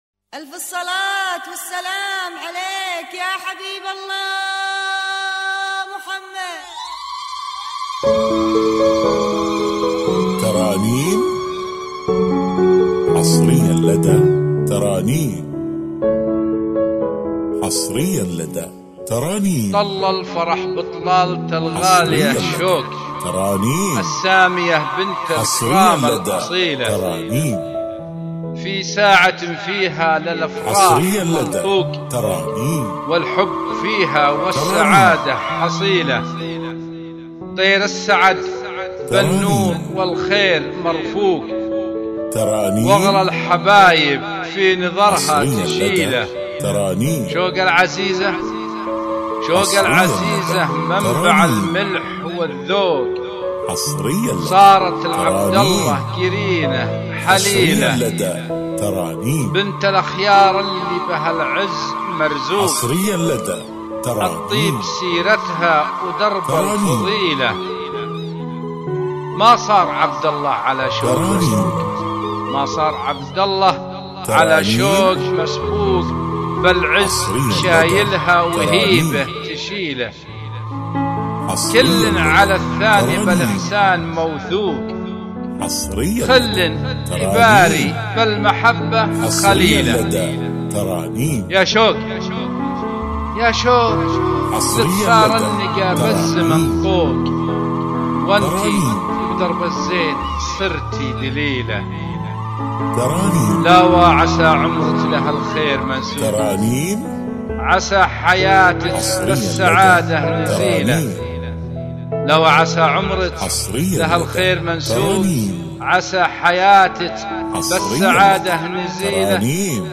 زفات
بدون موسيقى